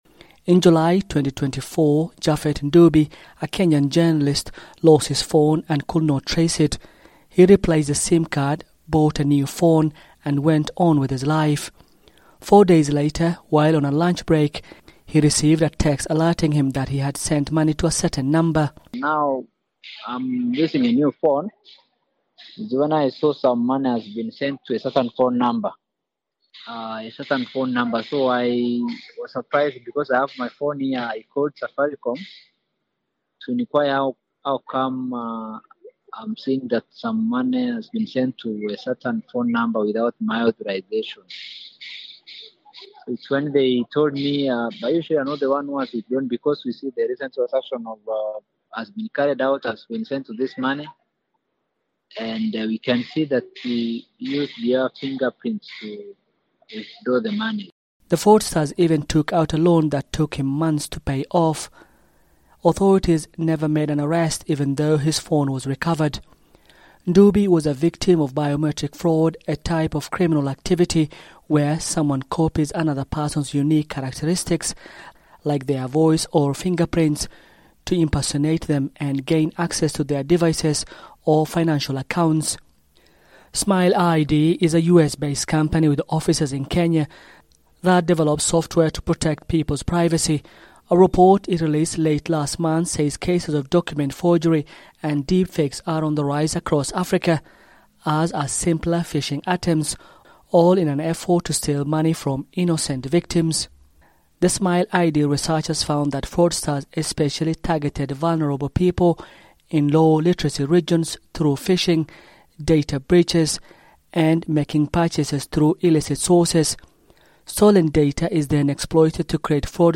A new report says the emergence of cheap artificial intelligence tools is leading to a wave of biometric fraud in Africa. The report says fraudsters are using AI to create fake documents, voices, and images that facilitate identity theft and financial crimes.